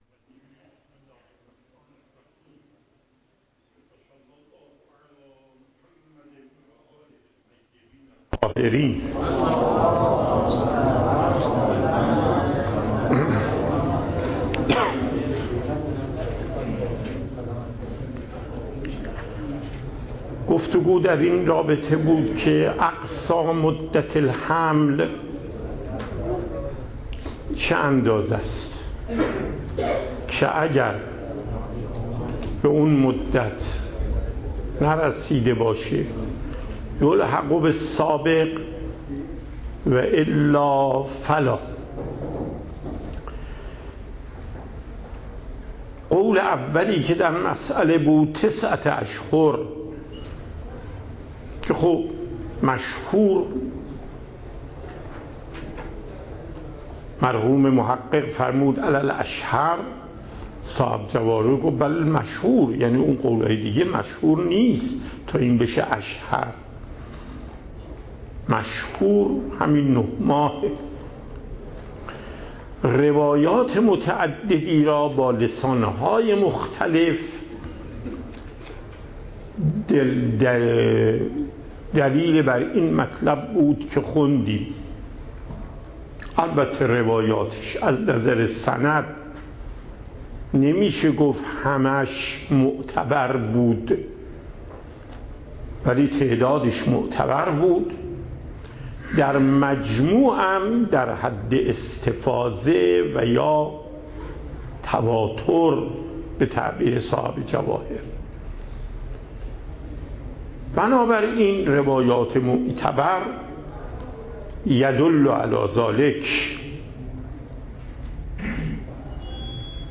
درس فقه آیت الله محقق داماد